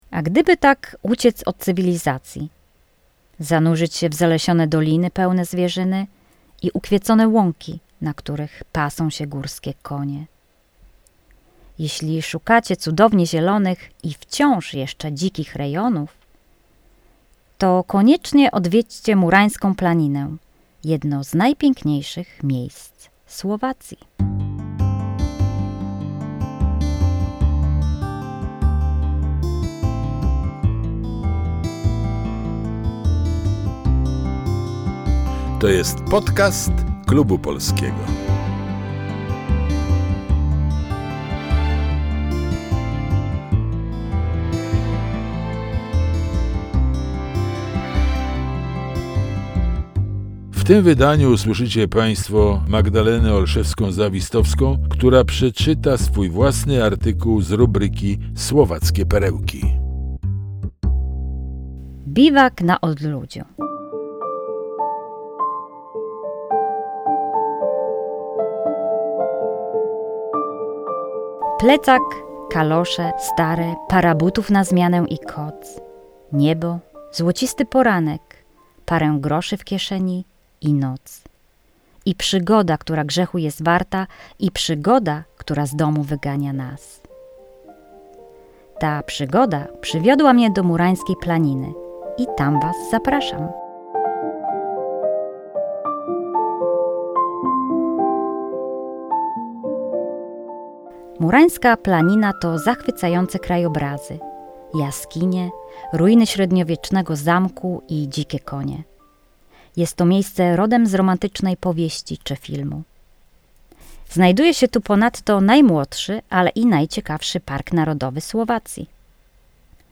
Czyta
Podkład muzyczny